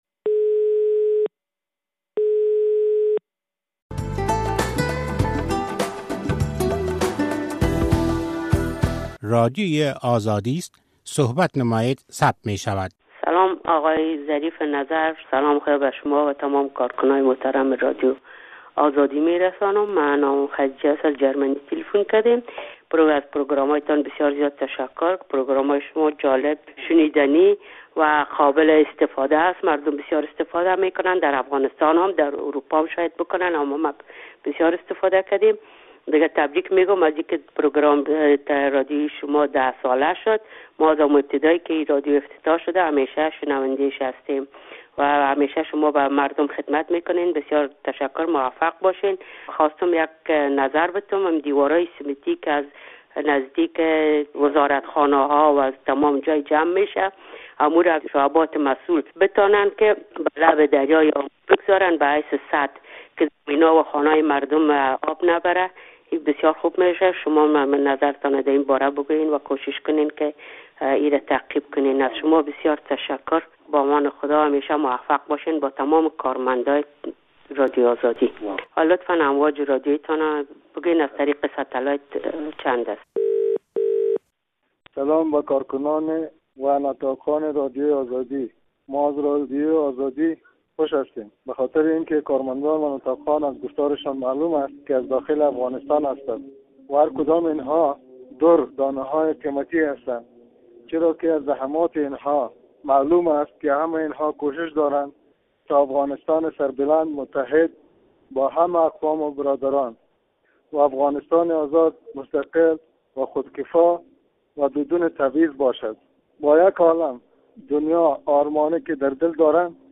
برگزیدۀ از پیام‌های تیلیفونی شما